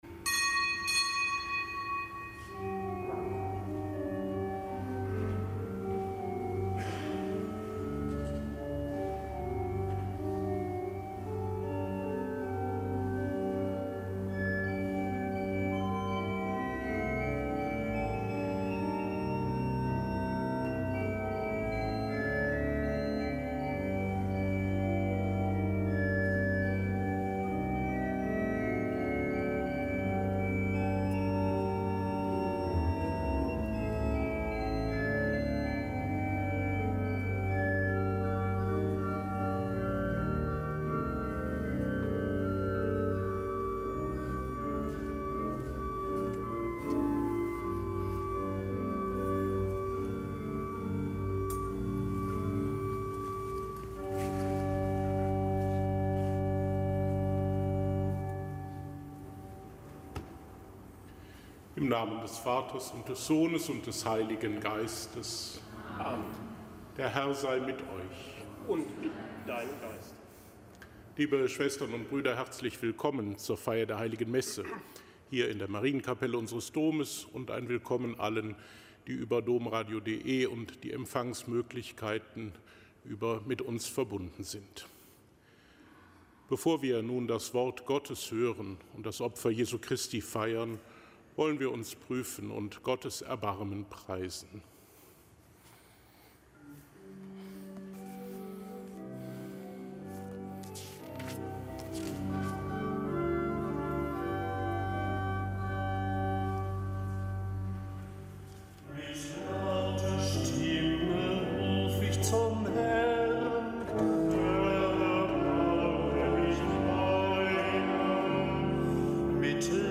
Kapitelsmesse am Dienstag der vierten Fastenwoche
Kapitelsmesse aus dem Kölner Dom am Dienstag der vierten Fastenwoche.